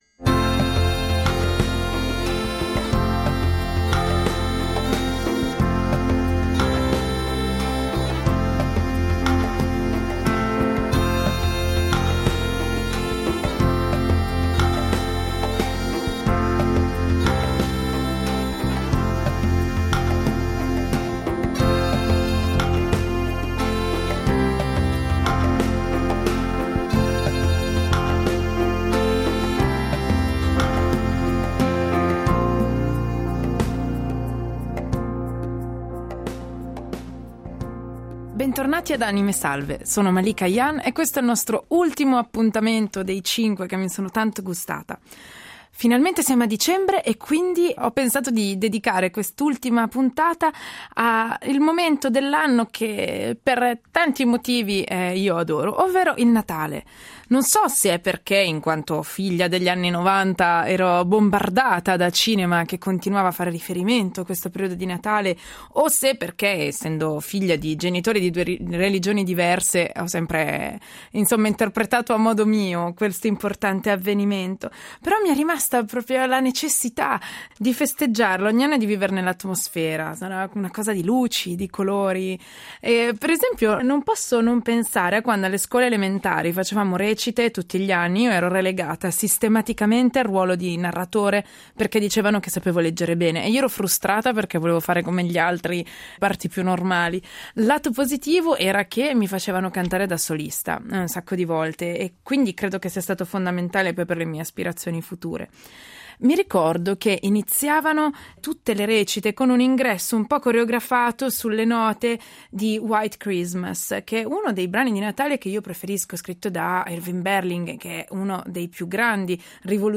Ospite di un ciclo di “Anime salve”, Malika Ayane ci ha raccontato di sé attraverso le canzoni che ha amato, quelle che hanno educato e forse indirizzato il suo ascolto nel corso degli anni, ma anche quelle che, quasi inevitabilmente, si è poi ritrovata a incidere su disco.